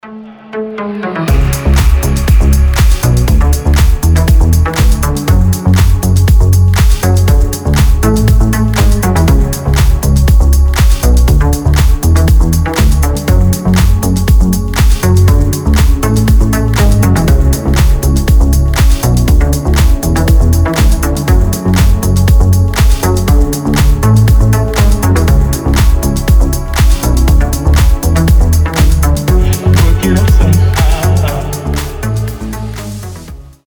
• Качество: 320, Stereo
deep house
атмосферные
басы
nu disco
Indie Dance
Атмосферный и чутка мрачный саунд